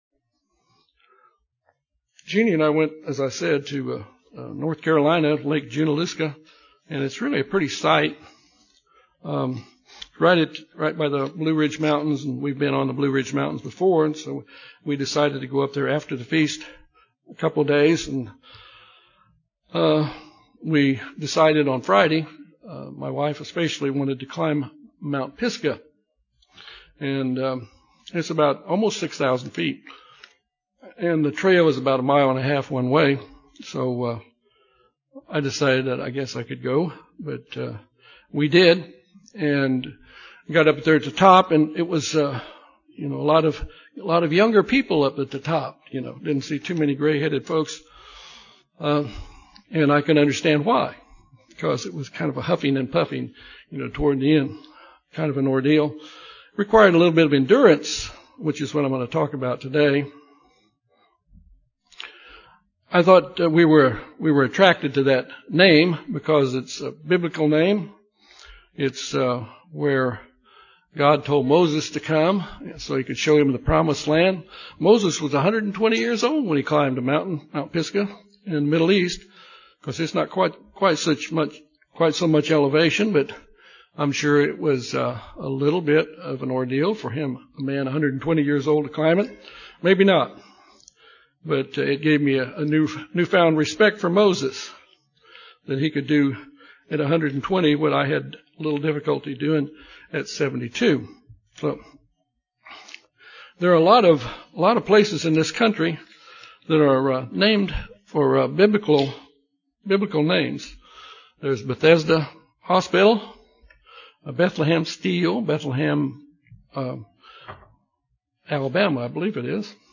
This sermon examines four examples of perseverance, Job, Noah, Moses, and William Tyndale, and how they apply to us today.
Given in Little Rock, AR